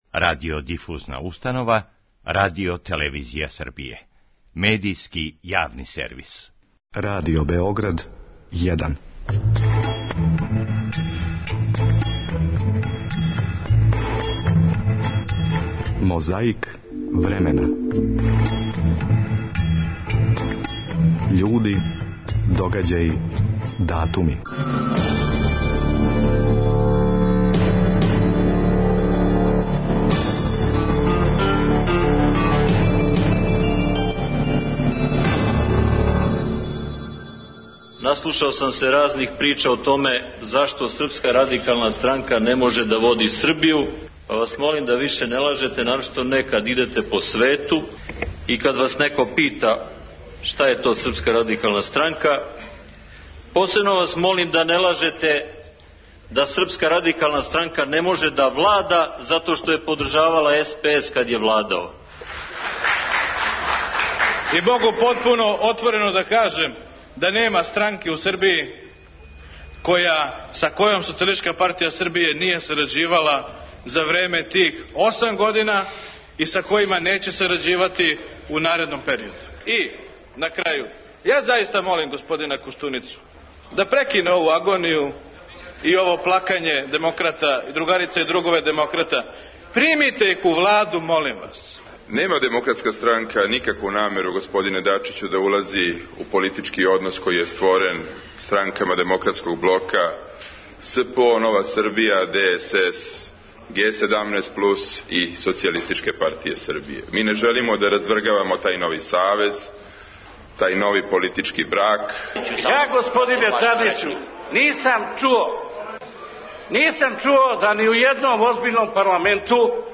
Том приликом говорио је члан председништва Лазар Мојсов.
О новонасталој ситуацији у студију Радио телевизије Сарајево разговарали су Биљана Плавшић, Фрањо Борас и Велибор Остојић.